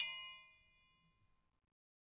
Triangle6-Hit_v2_rr2_Sum.wav